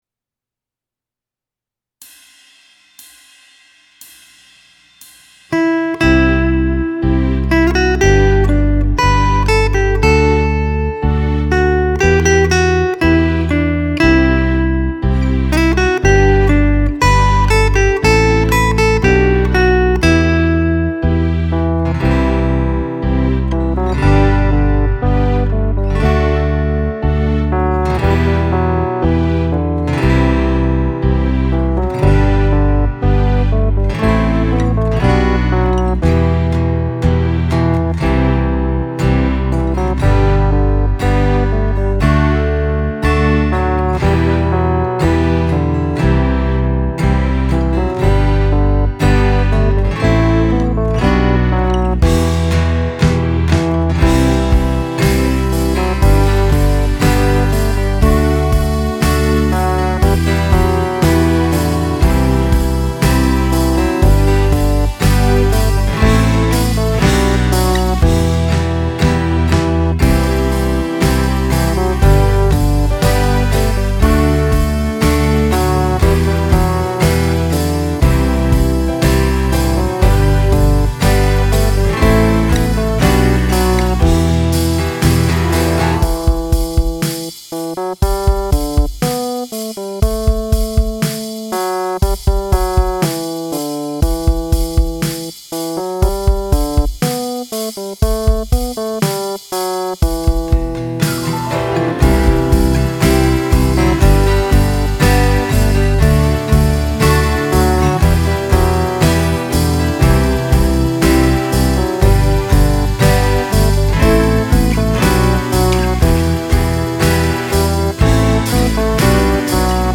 Intro (Piano+Guitar)